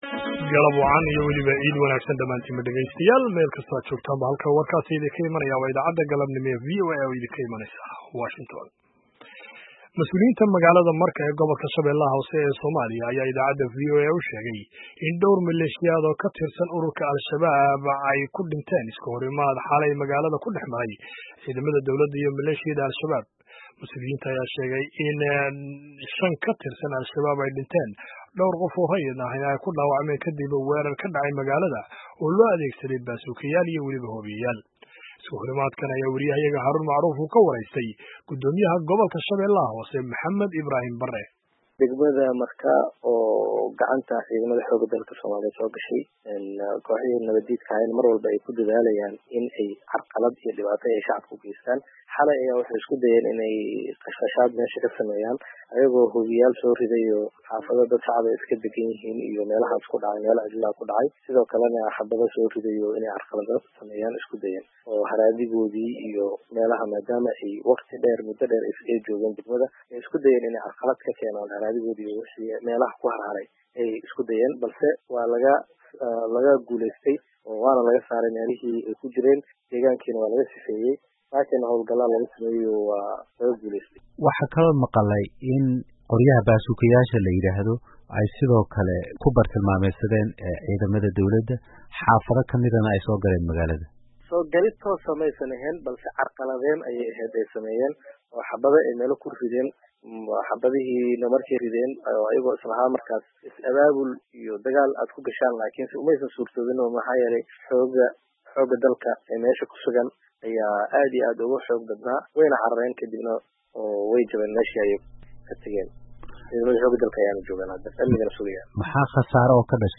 Dhagayso Waraysiga Guddomiyaha Shabeelaha Hoose